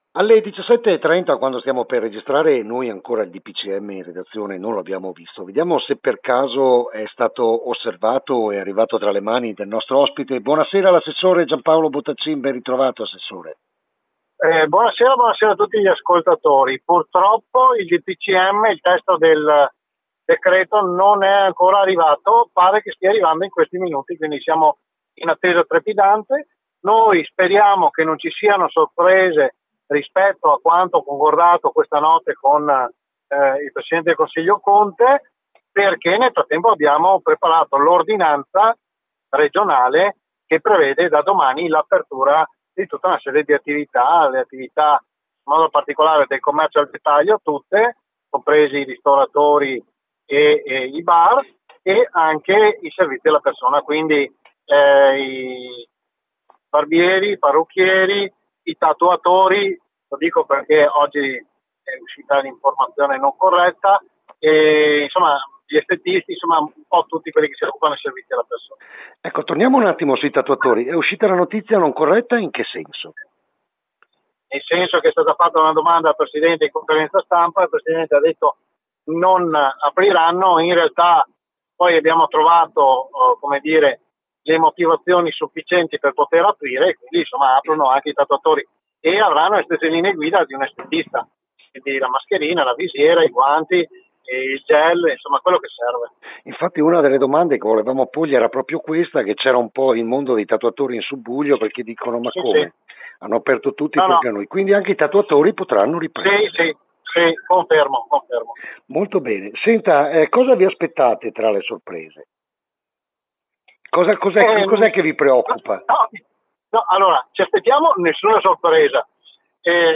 L’INTERVENTO A RADIO PIU’ DELL’ASSESSORE REGIONALE GIANPAOLO BOTTACIN